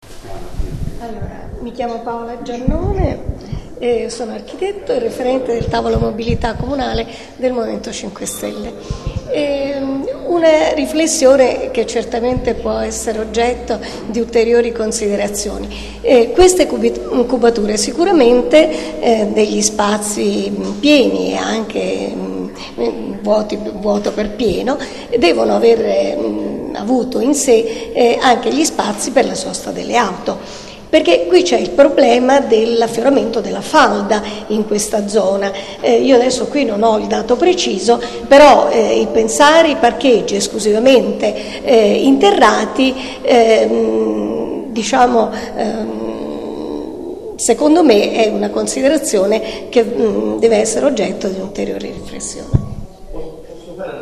Piano di recupero del Quartiere Città della Scienza - Ascolto audio dell'incontro